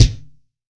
B.B KICK 2.wav